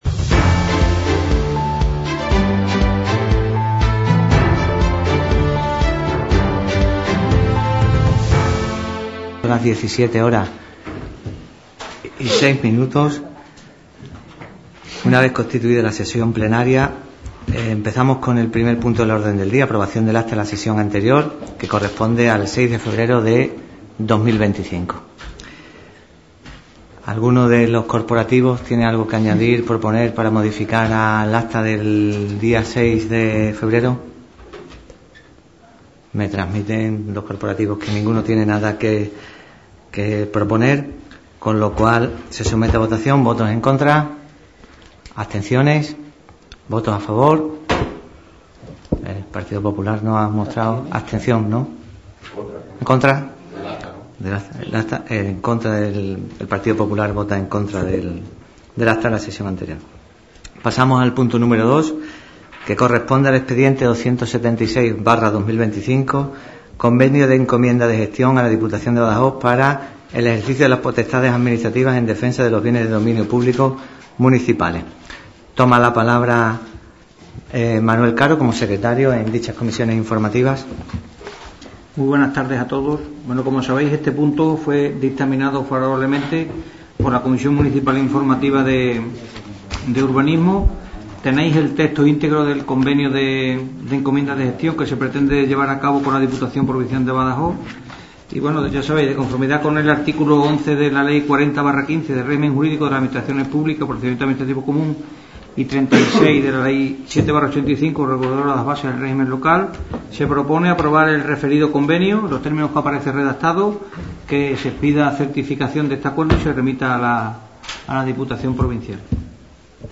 Sesión celebrada en el Ayuntamiento de Guareña.